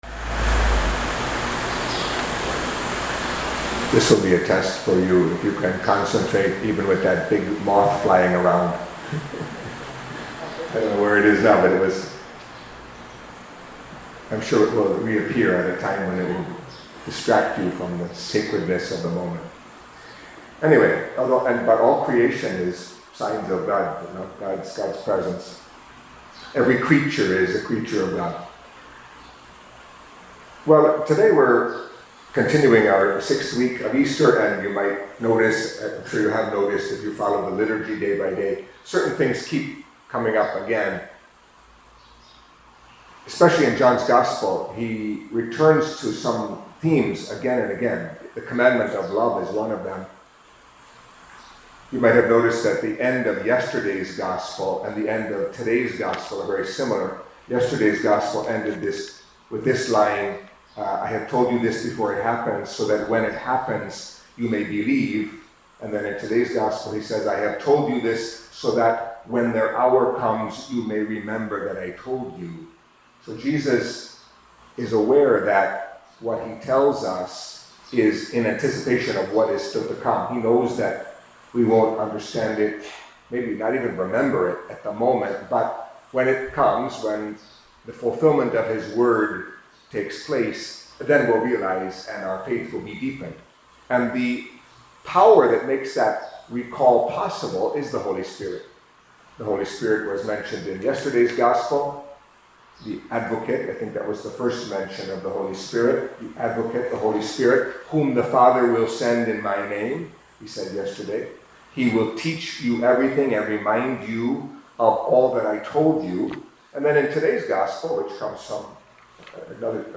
Catholic Mass homily for Monday of the Sixth Week of Easter